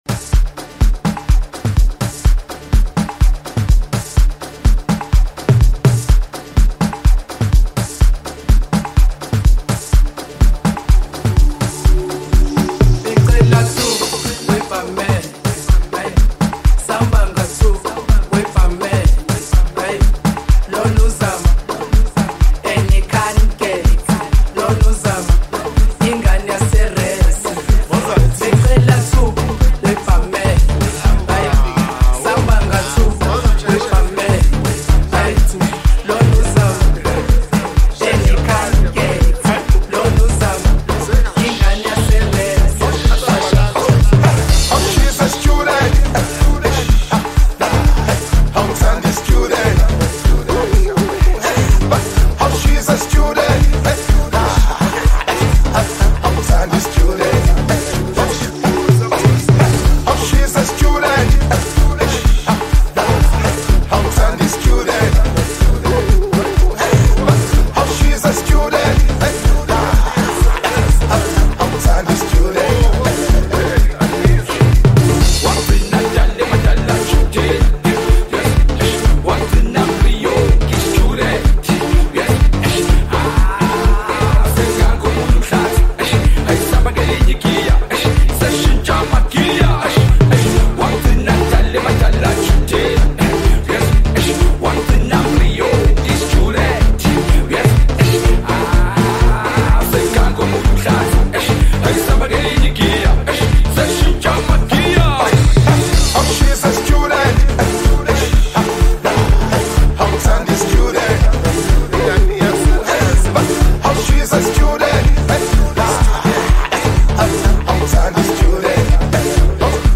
Gqom